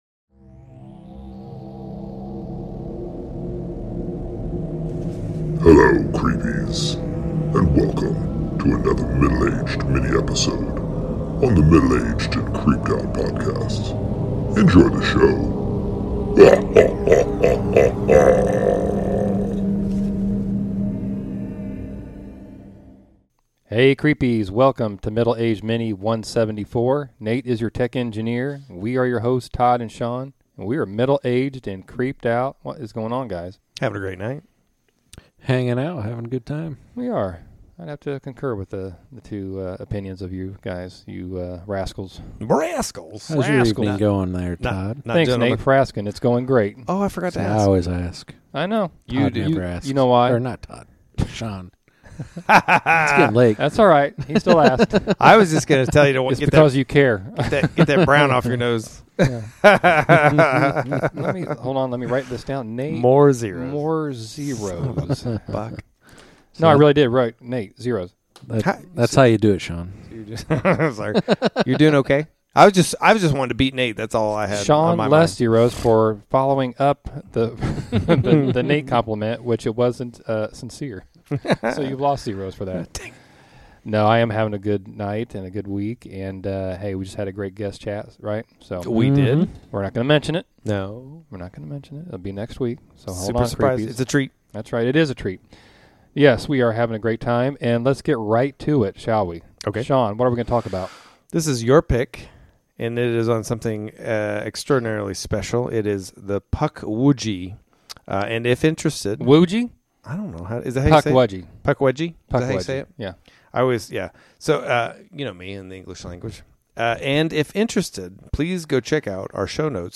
The guys go full...or actually "mini"...cryptid lore. Enjoy this fun but creepy discussion on...Pukwudgies!!!